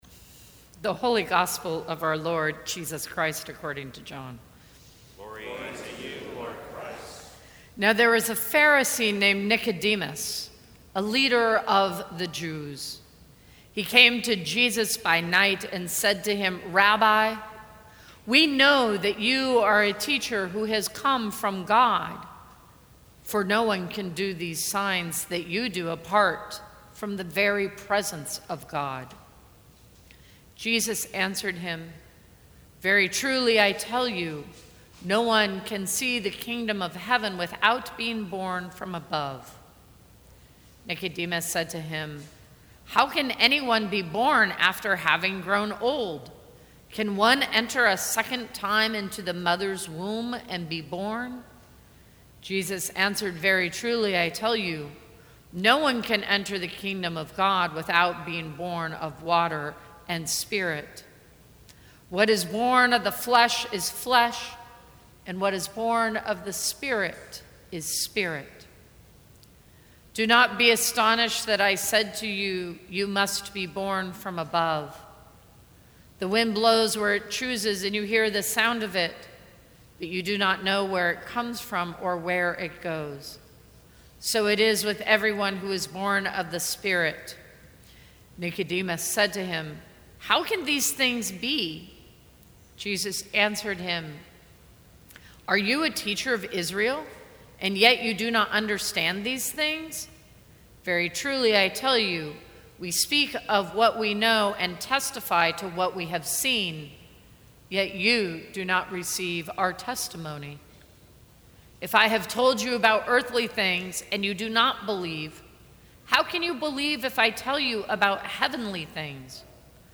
Sermons from St. Cross Episcopal Church Nicodemus Is Us May 29 2018 | 00:14:49 1x 00:00 / 00:14:49 Subscribe Share Apple Podcasts Spotify Overcast RSS Feed Share Link Embed